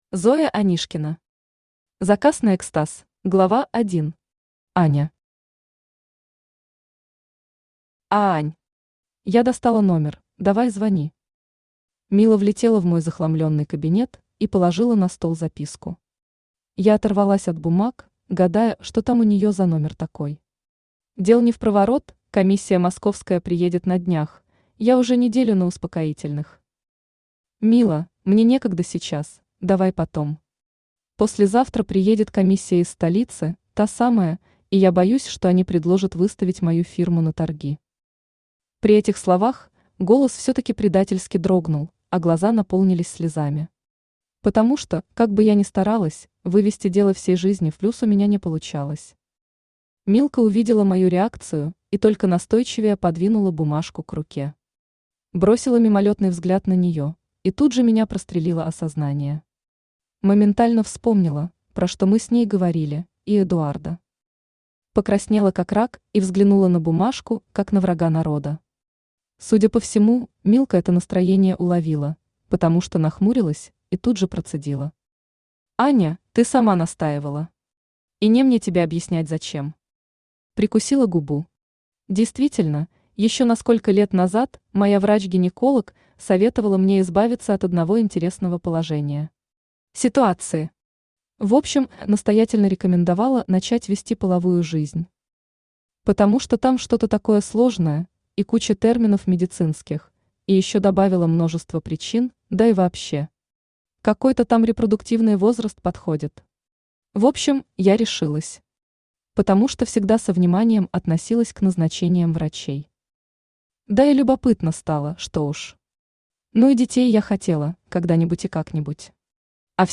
Aудиокнига Заказ на экстаз Автор Зоя Анишкина Читает аудиокнигу Авточтец ЛитРес.